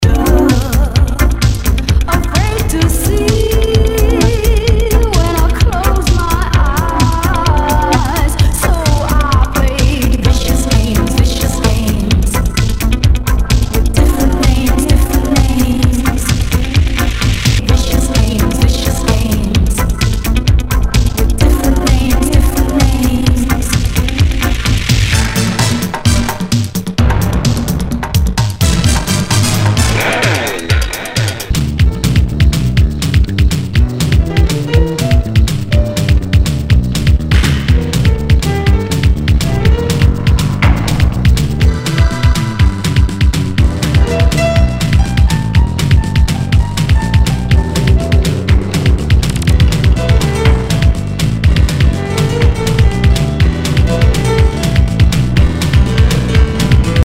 HOUSE/TECHNO/ELECTRO
ナイス！シンセ・ポップ / ヴォーカル・ハウス・クラシック！
[VG ] 平均的中古盤。スレ、キズ少々あり（ストレスに感じない程度のノイズが入ることも有り）